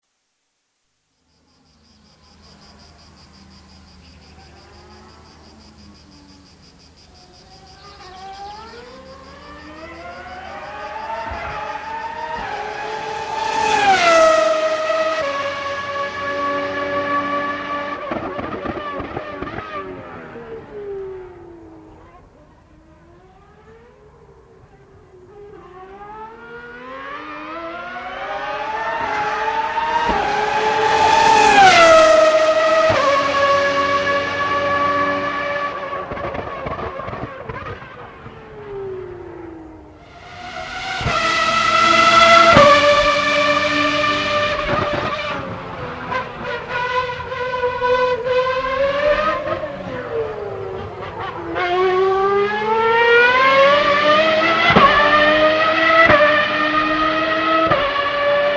Звуки Формулы-1
На этой странице собраны лучшие звуки Формулы-1: от оглушительного рева гибридных двигателей до свиста ветра на прямых.
Гул проносящихся болидов Формулы-1 с трассы